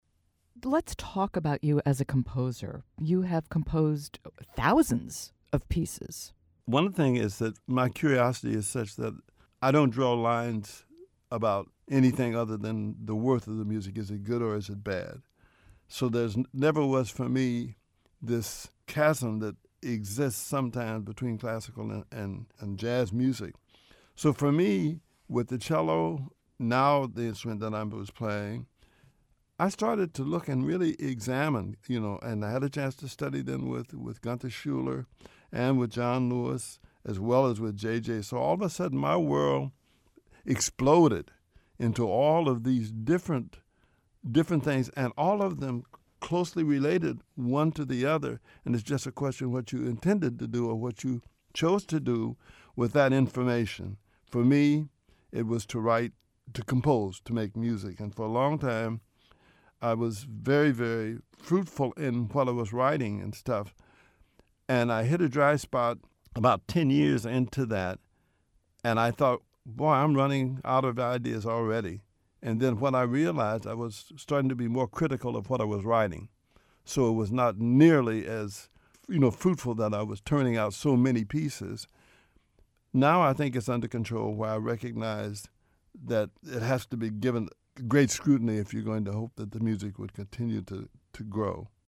Transcript of interview with David Baker